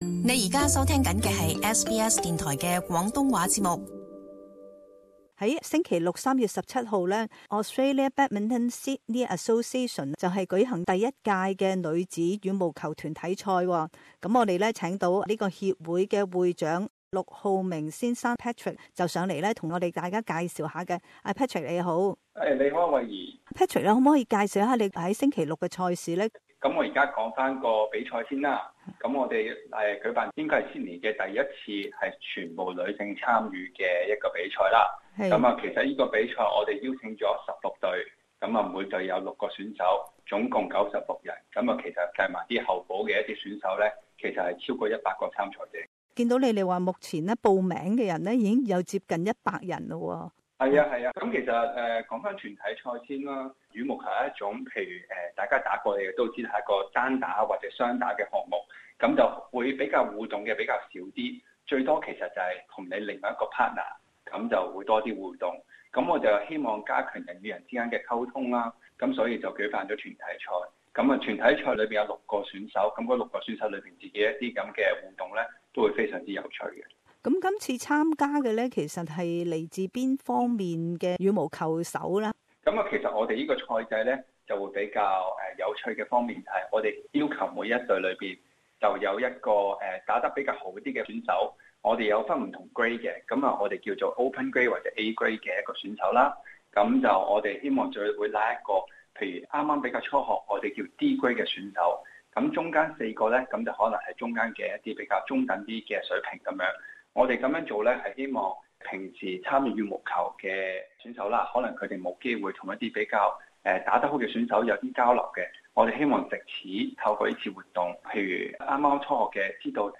【社区专访】女子羽毛球团体赛